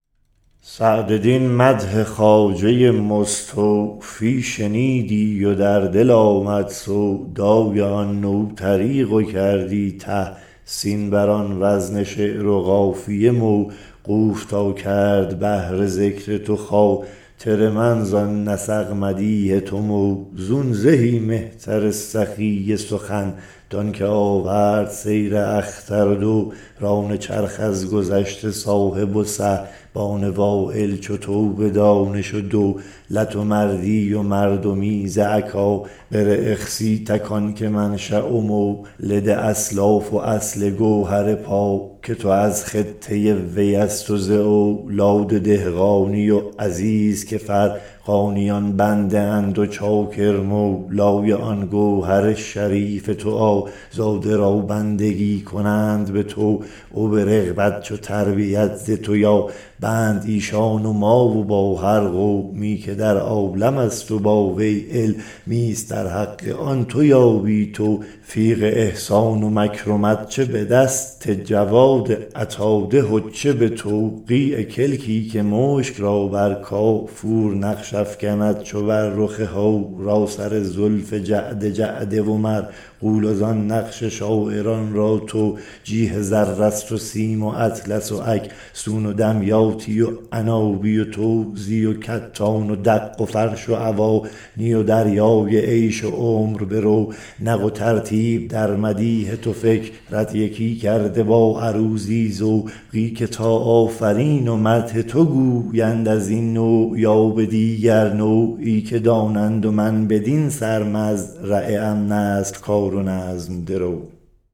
خوانش نسخه چاپی این شعر را از اینجا ببینید و دکلمه نسخه خطی مجلس شورای ملی را از